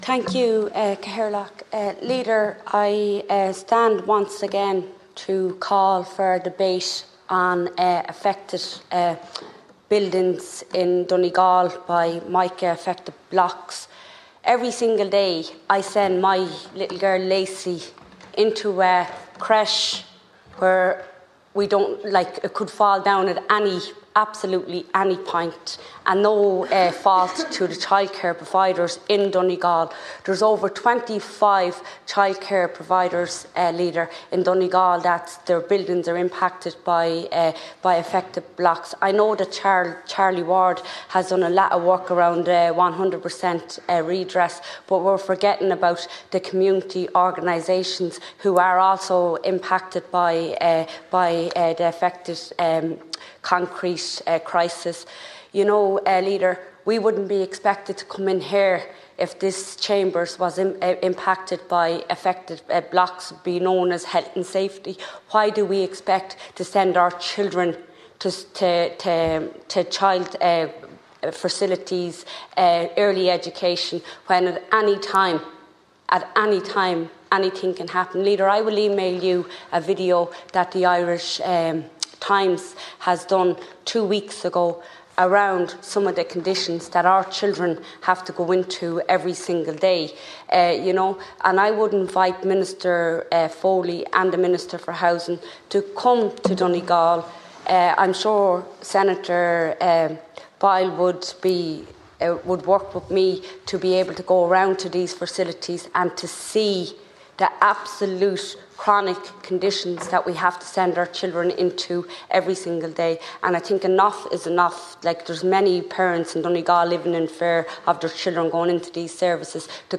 In the Seanad Chamber, renewed calls have been made for a scheme to be established for non-residential buildings affected by defective concrete.